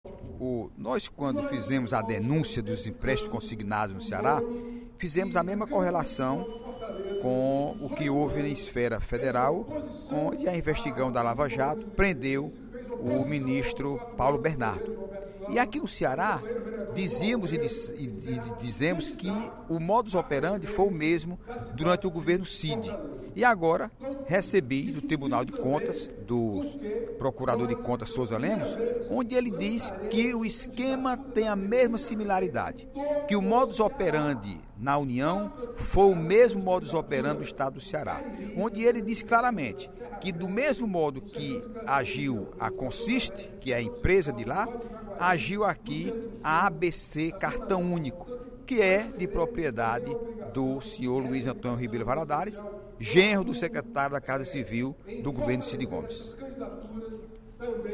O deputado Heitor Férrer (PDT) afirmou, nesta sexta-feira (08/07), durante o  primeiro expediente da sessão plenária, que um relatório enviado pelo Tribunal de Contas do Estado do Ceará (TCE) atestaria que o “esquema” dos empréstimos consignados no Ceará foi similar ao que ocorreu no Governo Federal e acabou levando à prisão do ex-ministro Paulo Bernardo.